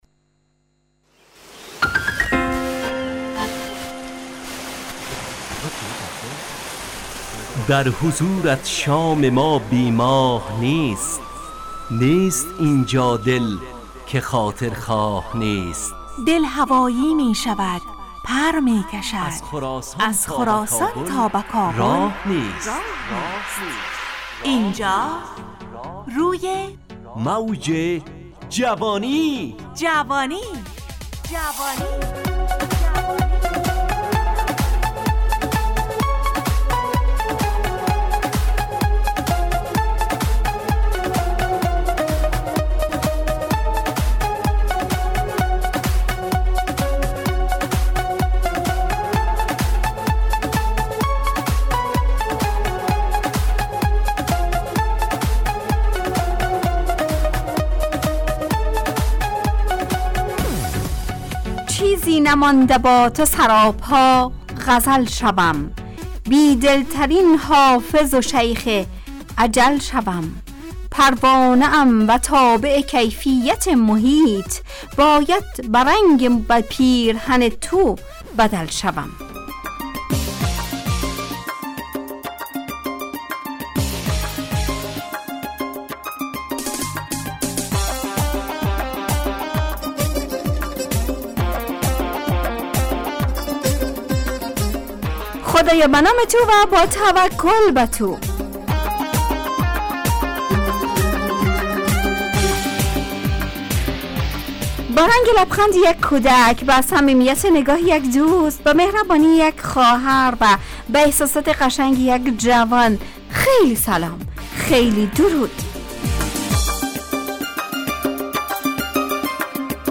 همراه با ترانه و موسیقی مدت برنامه 70 دقیقه . بحث محوری این هفته (غم وشادی) تهیه کننده